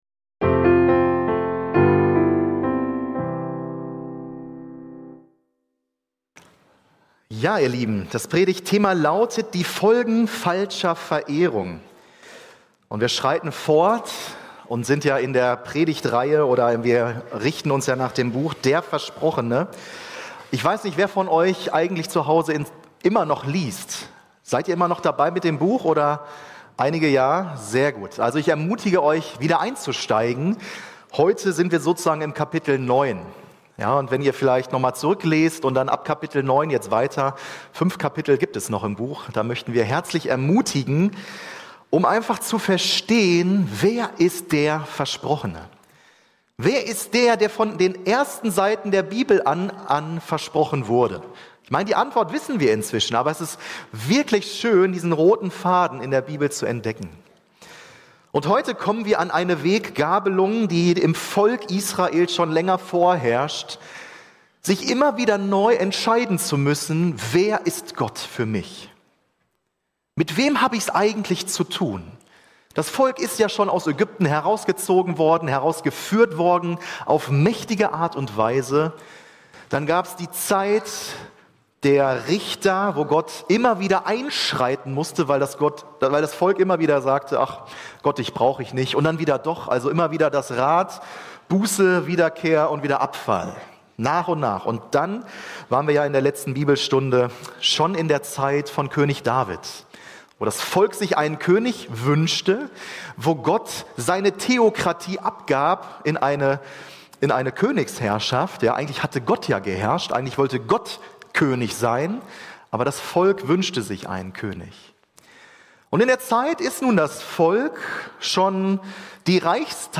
Hauptpredigt